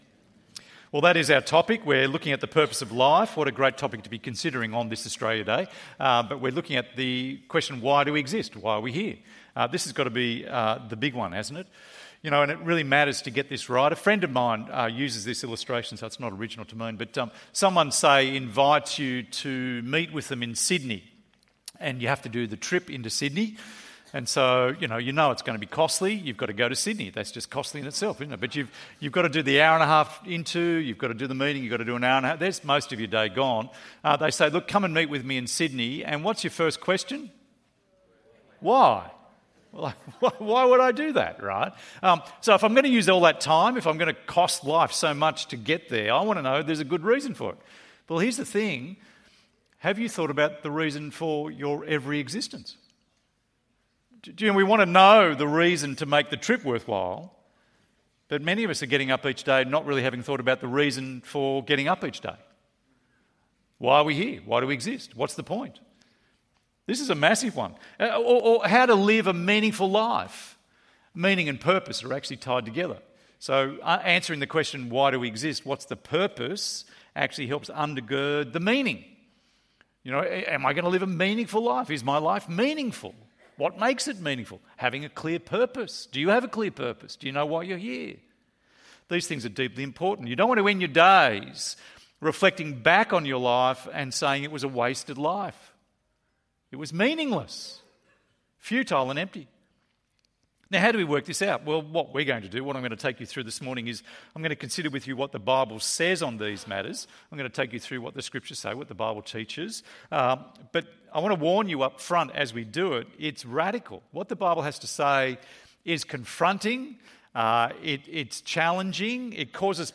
Why do we exist? ~ EV Church Sermons Podcast